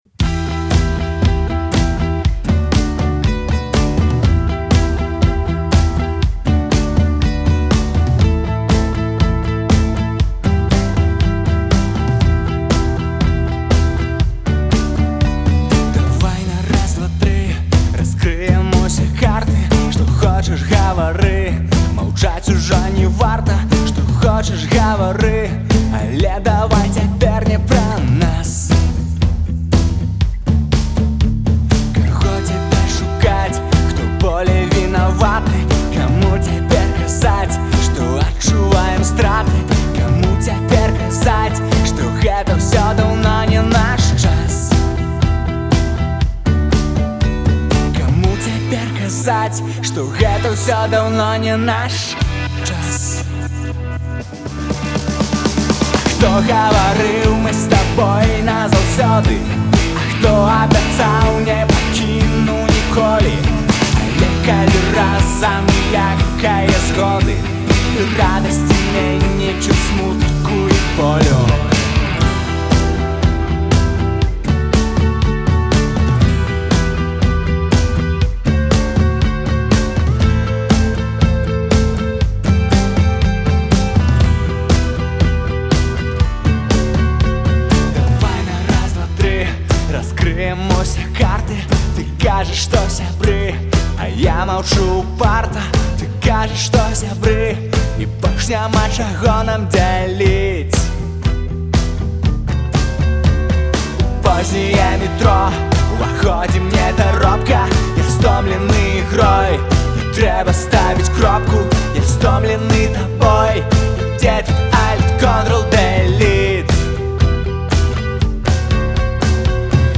гітарыст
Першая студыйная праца
дынамічная песня